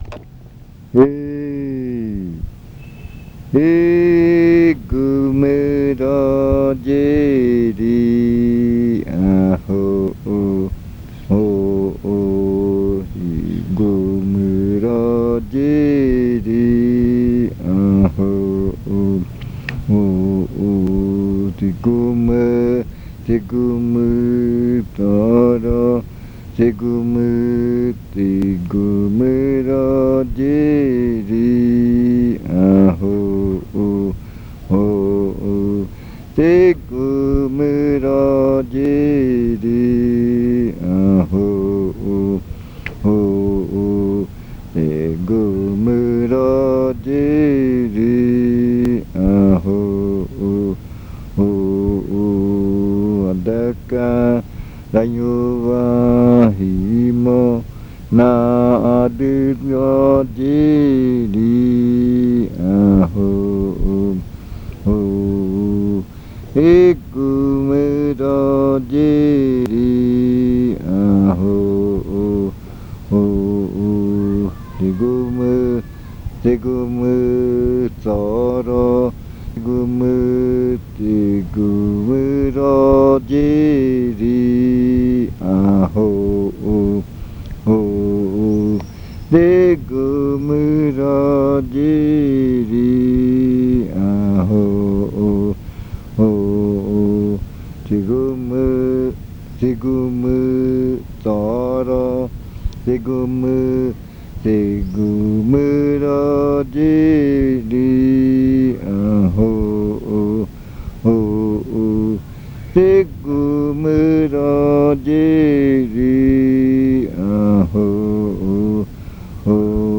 Se canta a cualquier hora de la noche, para no dejar un espacio silencioso del baile en la maloca.
It is sung at any time of the night, so as not to leave a silent space for the dance in the maloca.
This chant is part of the collection of chants from the Yuakɨ Murui-Muina
Cantos de yuakɨ